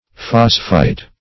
phosphite - definition of phosphite - synonyms, pronunciation, spelling from Free Dictionary Search Result for " phosphite" : The Collaborative International Dictionary of English v.0.48: Phosphite \Phos"phite\, n. (Chem.) A salt of phosphorous acid.